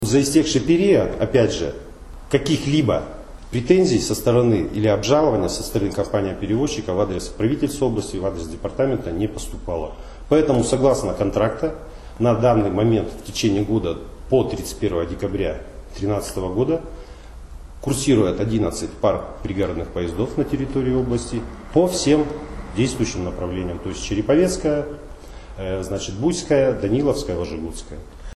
Правительство Вологодской области считает, что компания РЖД неправомерно обвиняет его в отказе выплачивать денежную компенсацию за пригородные перевозки. Об этом заявил начальник Управления транспорта Департамента дорожного хозяйства и транспорта Валерий Попов.
Валерий Попов рассказывает о пригородных поездах